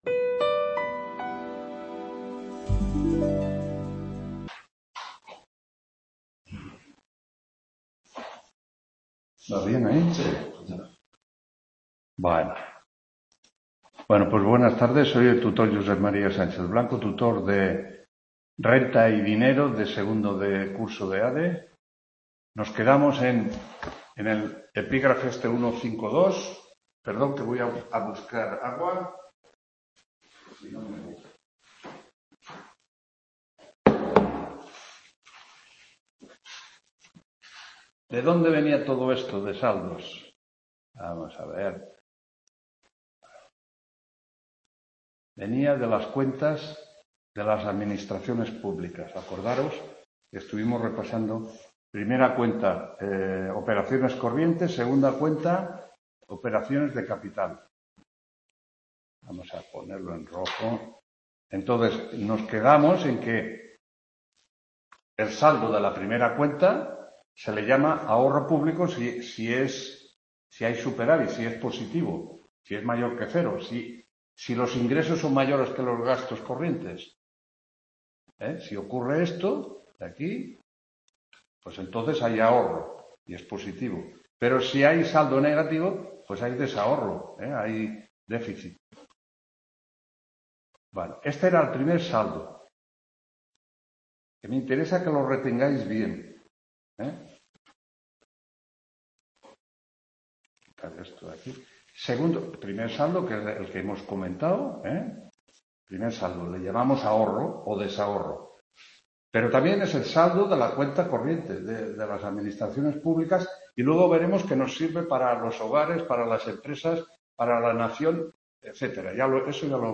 3ª TUTORÍA RENTA Y DINERO CONTABILIDAD NACIONAL (III)…